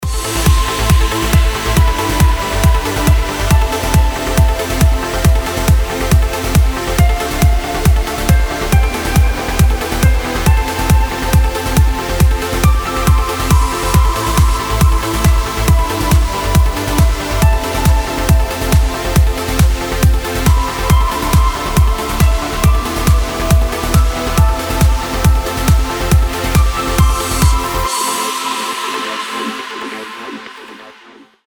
красивые
dance
Electronic
без слов
Trance